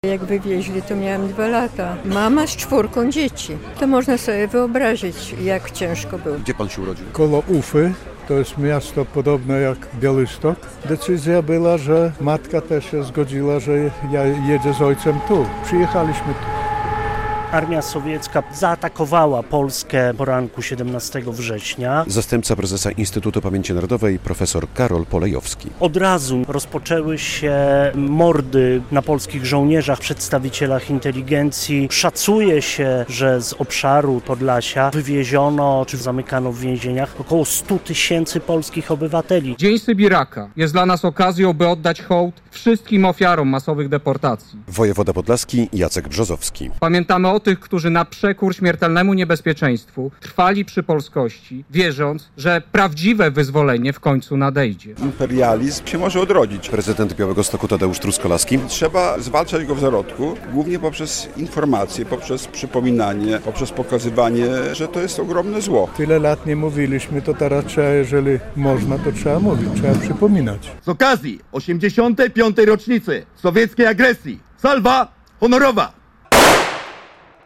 W uroczystościach przy Muzeum Pamięci Sybiru w Białymstoku wzięli udział świadkowie wywózek w głąb Rosji, którzy z poruszeniem wspominają te dramatyczne chwile.